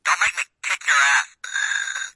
描述：录制的遥控装置，是一块声板，包含了《海狸和烟蒂头》的原始声音，随书附送的"这很糟糕，改变它"。酷！